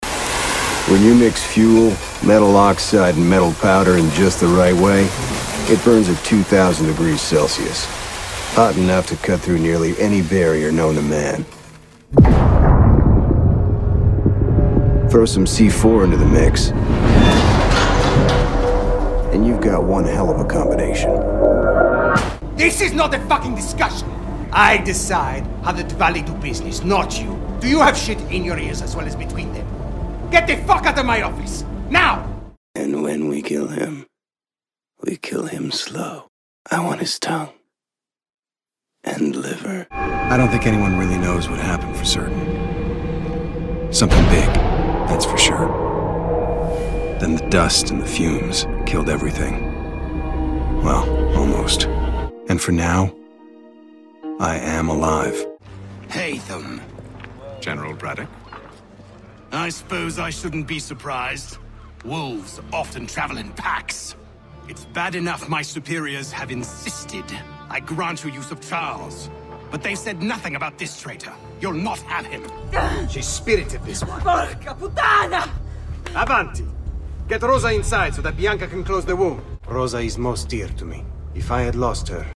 Video games - EN